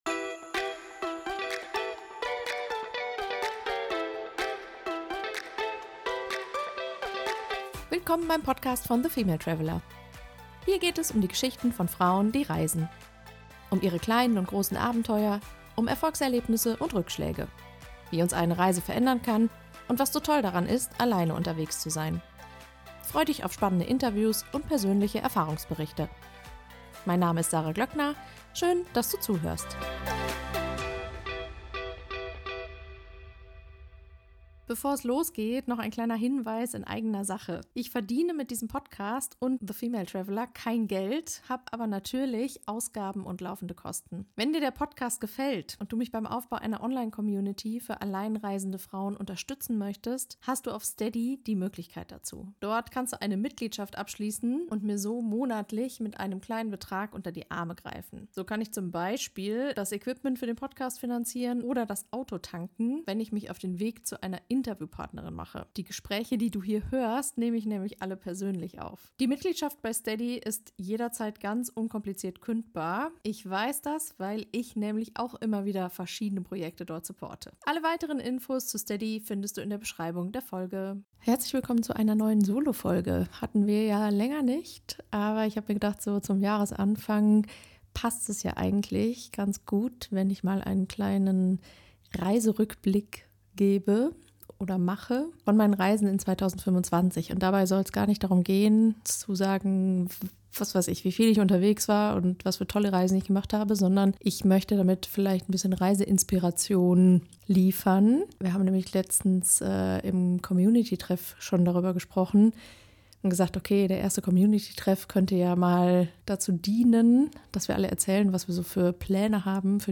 Reiserückblick 2025 - Solofolge ~ The Female Traveller Podcast